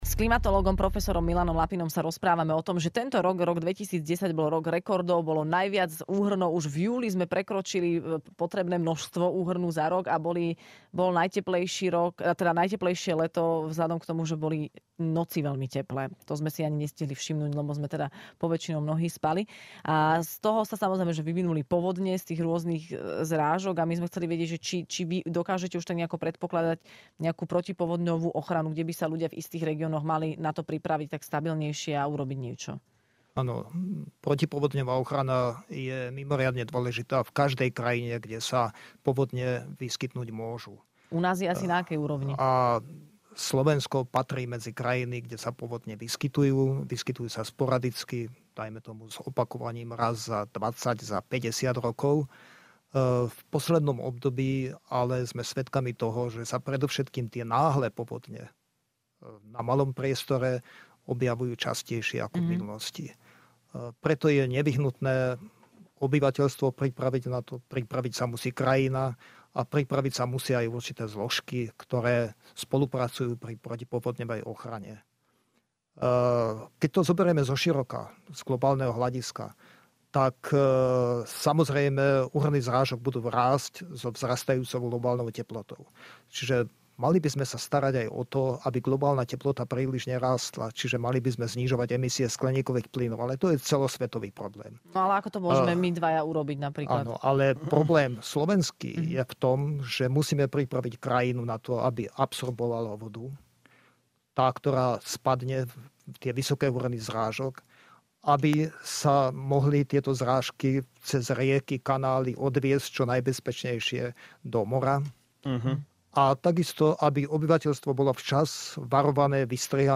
Počasie si s nami stále robí čo chce, a tak sme si do štúdia zavolali klimatológa